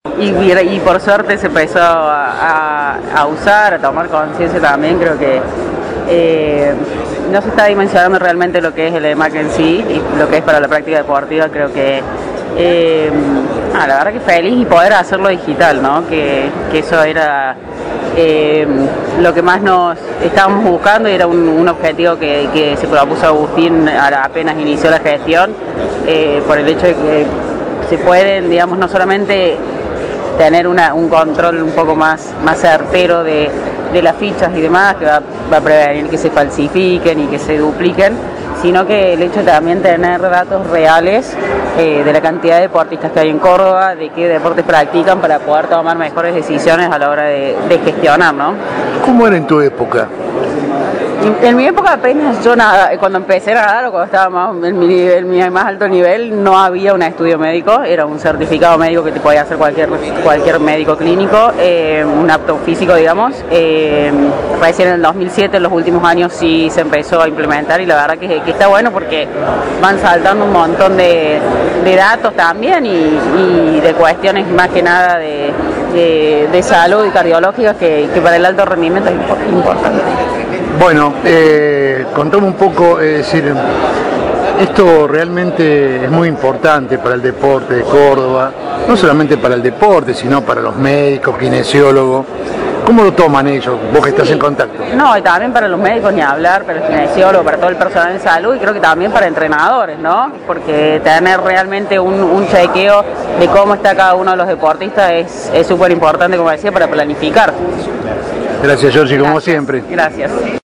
Córdoba y la modernización de la salud deportiva. Voces de los protagonistas de una Jornada Histórica en el Polo Deportivo Kempes.